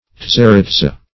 Tzaritza \Tza*rit"za\
tzaritza.mp3